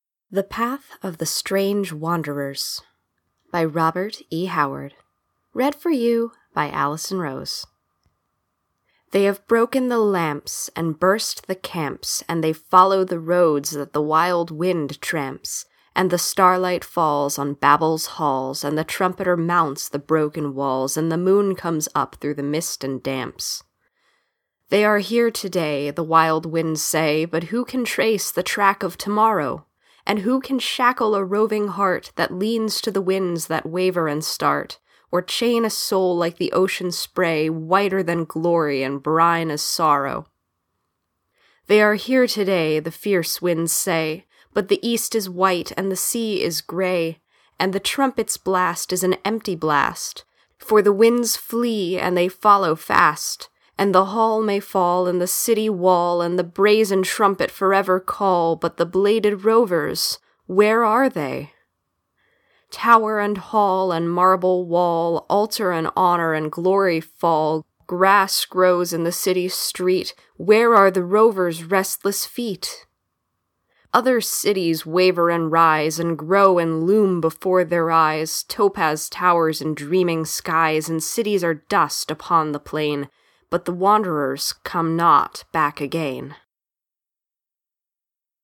Audio Recordings of Poems by Robert E. Howard